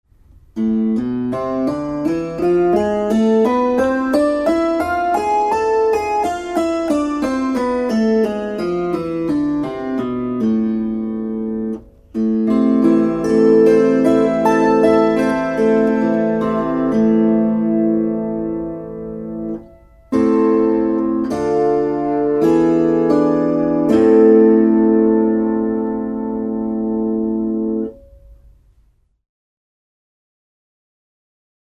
Kuuntele a-molli. gis Opettele duurit C G D A E F B Es As mollit e h fis cis d g c f Tästä pääset harjoittelun etusivulle.